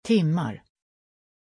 Aussprache von Tim
pronunciation-tim-sv.mp3